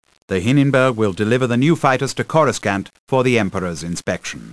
BriefingOfficer-Hininbirg-TIEFighter.ogg